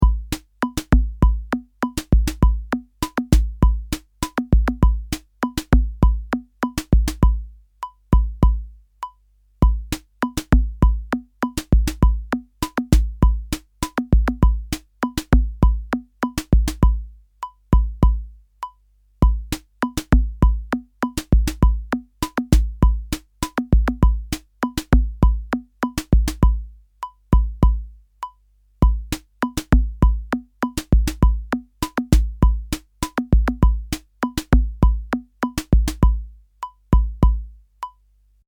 Bucle de percusión electrónica
Música electrónica
percusión
repetitivo
rítmico
sintetizador